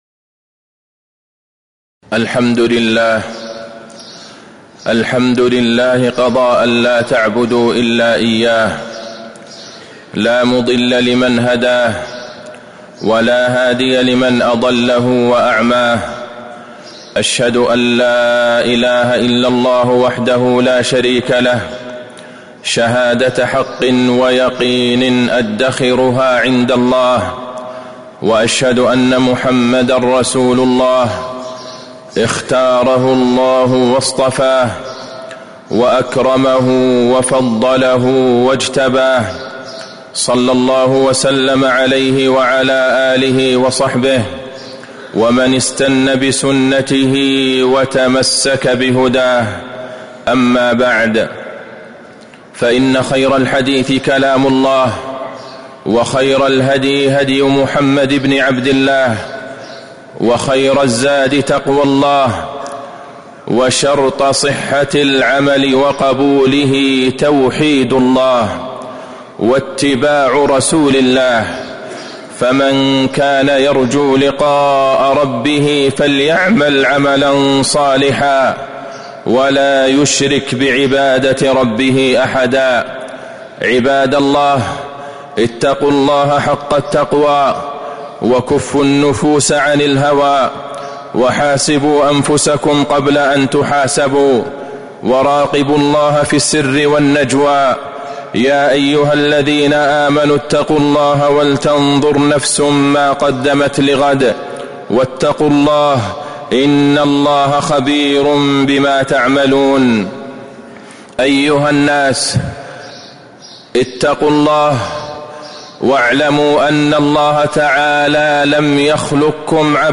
تاريخ النشر ٢٠ شوال ١٤٤٦ هـ المكان: المسجد النبوي الشيخ: فضيلة الشيخ د. عبدالله بن عبدالرحمن البعيجان فضيلة الشيخ د. عبدالله بن عبدالرحمن البعيجان حقيقة التوحيد وأهميته